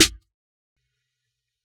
2 Snare -whyutrappinsohard (No Heart).wav